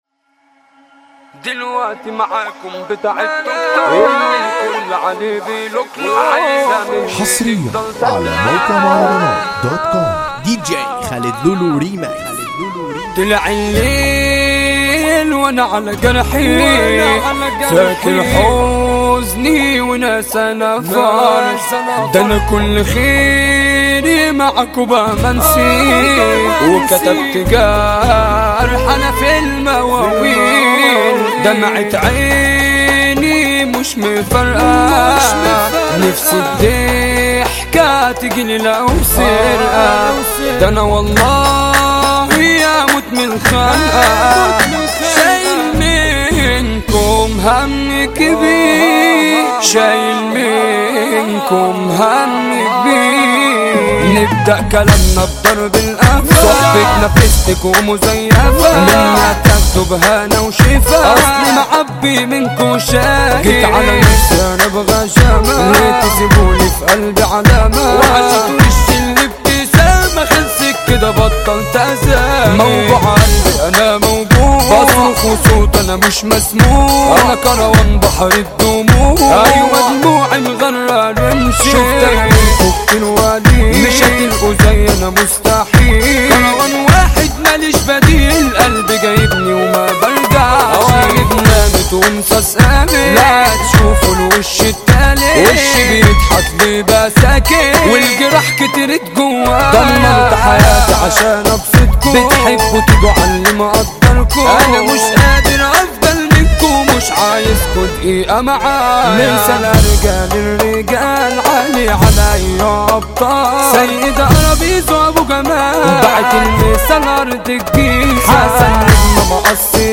مهرجانات جديدة